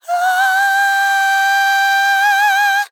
TEN VOCAL FILL 12 Sample
Categories: Vocals Tags: dry, english, female, fill, sample, TEN VOCAL FILL, Tension
POLI-VOCAL-Fills-100bpm-A-12.wav